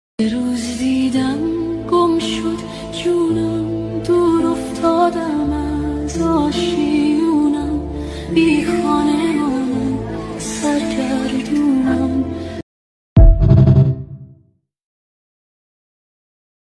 ژانر: پاپ
🎤 خواننده : صدای زن